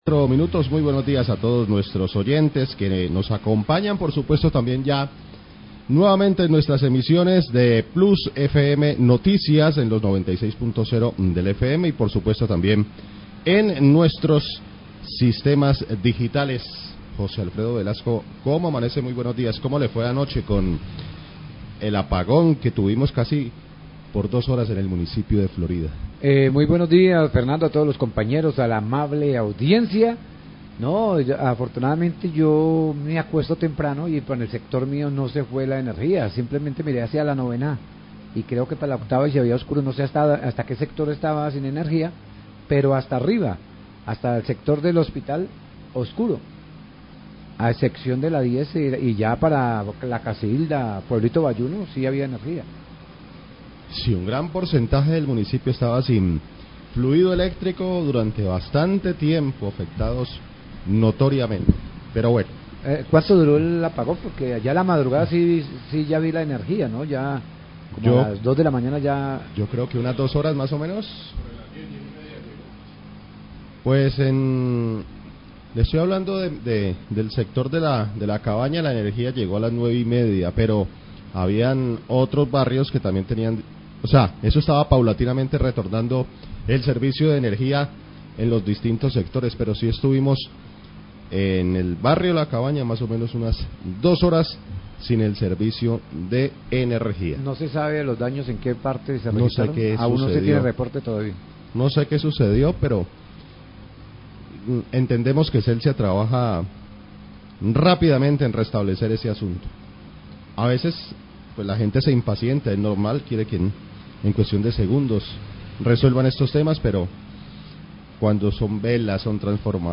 Radio
Periodistas inician el informativo comentando sobre el porlongado corte de energía en la noche anterior en el municipio de Florida, que afectó el suministro eléctrico para gran parte de la ciudad. Luego afirman que Celsia estuvo adelantando operaciones para el restablecimiento del servicio de energía, que se dió en horas de la madrugada.